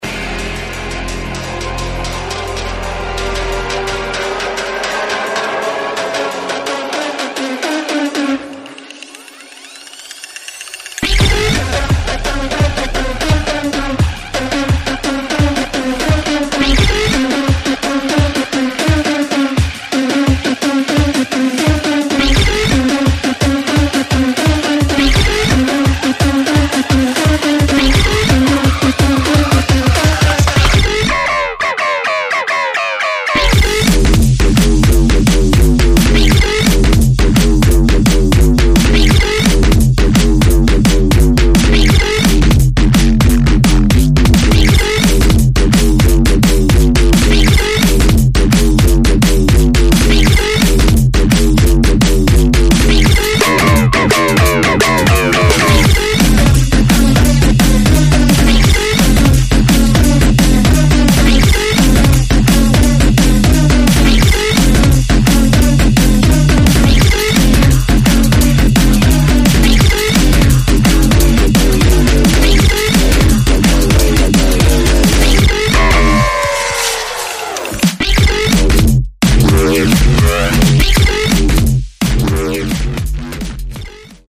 [ DRUM'N'BASS / JUNGLE ]